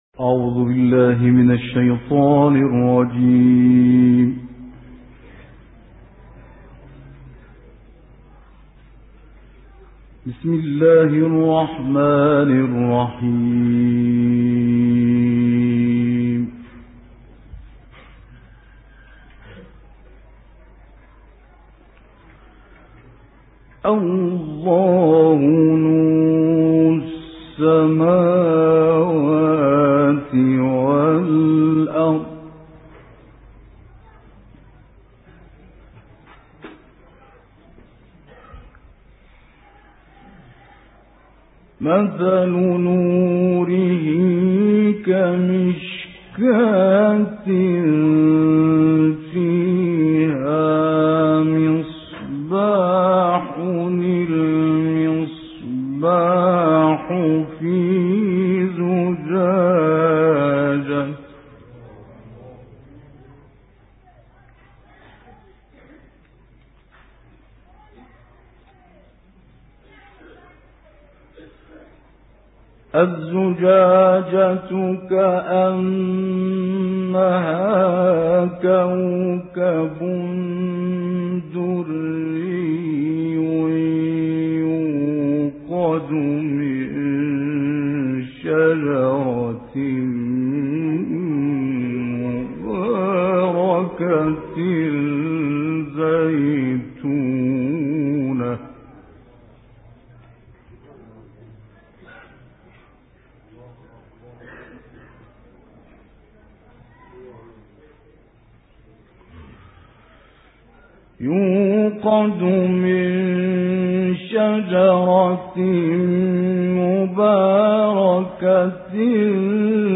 گروه فعالیت‌های قرآنی: تلاوت مجلسی شامل آیاتی از سوره مبارکه نور